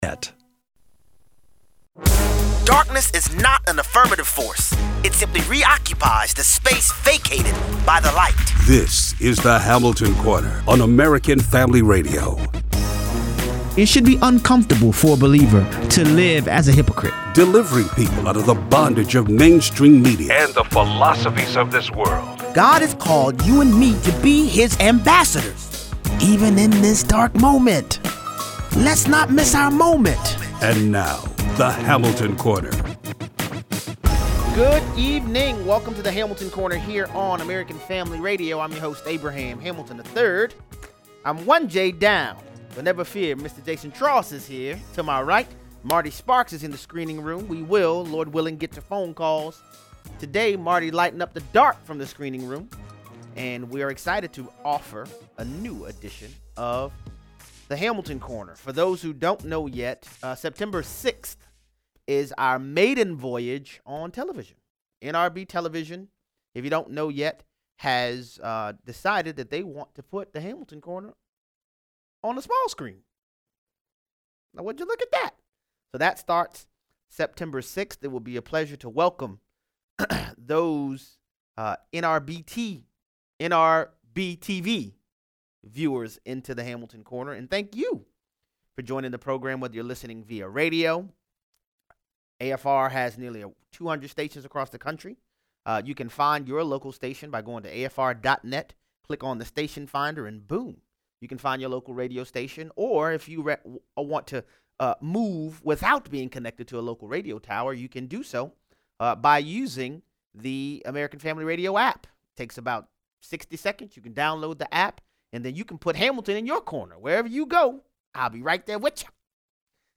We are witnessing public attempts to redefine morality away from God’s Word. Callers weigh in.